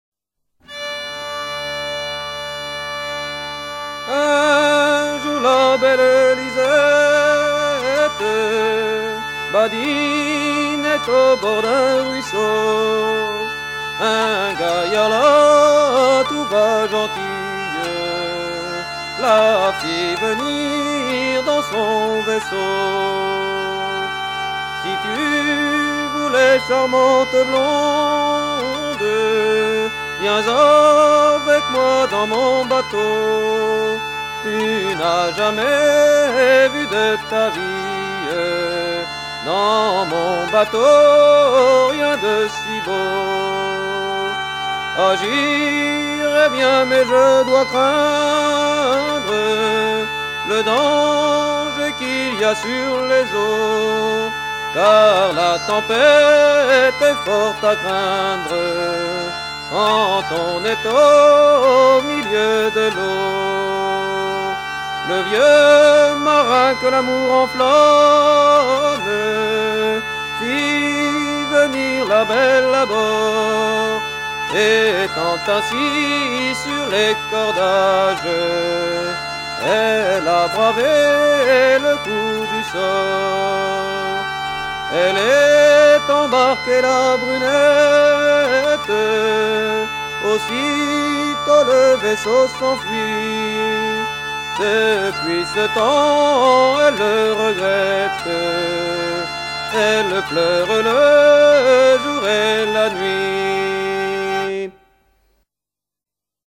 Enregistré en 1960, chanté par un chanteur anonyme de Ocqueville (76)
Genre strophique